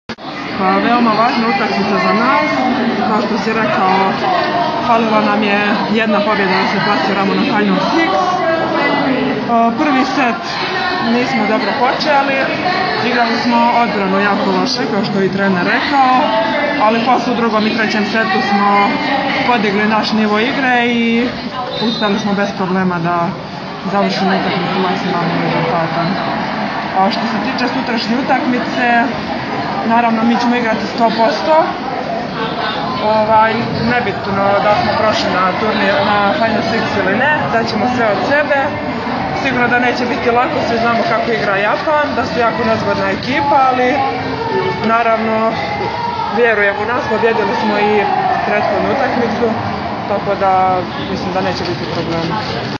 IZJAVA TIJANE BOŠKOVIĆ